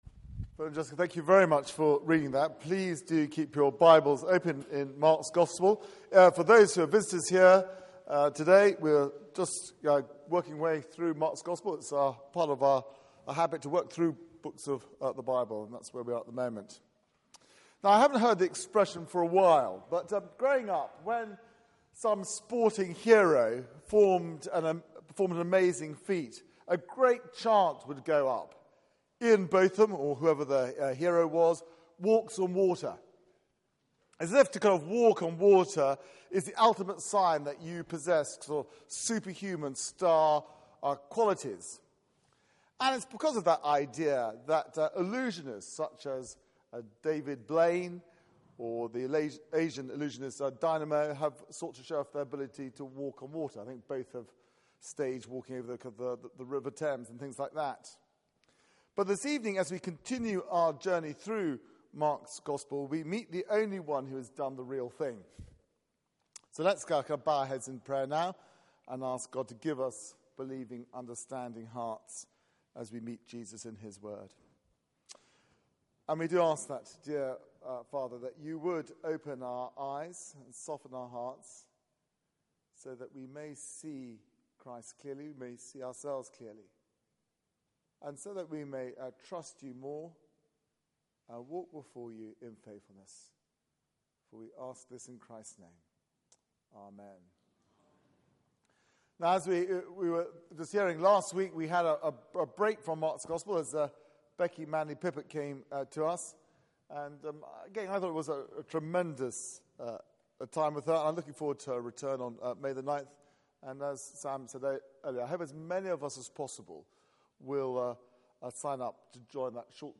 Passage: Mark 6:45-56 Service Type: Weekly Service at 4pm